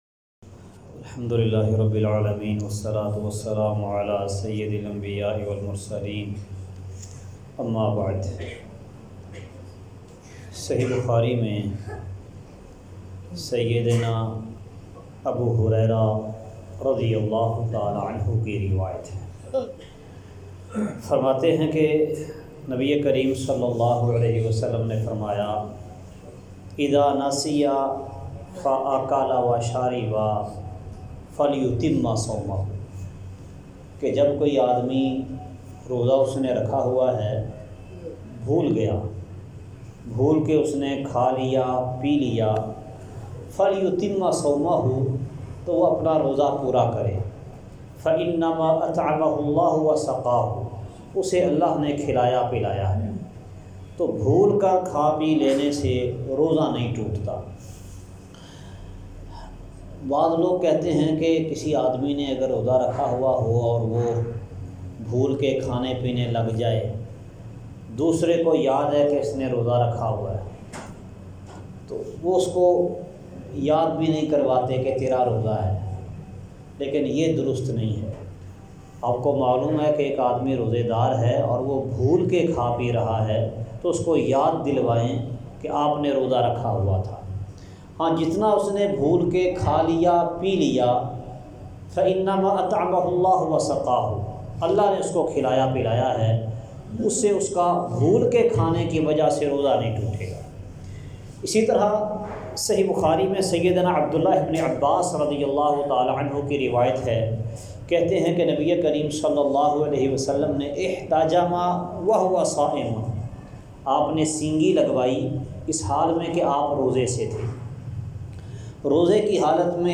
درس کا خلاصہ